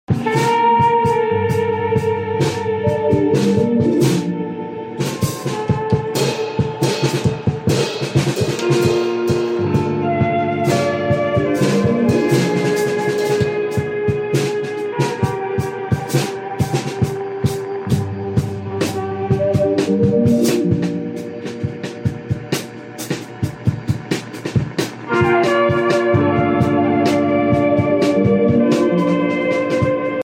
Playing drums over my playlist sound effects free download
drums